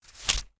page-flip-19.ogg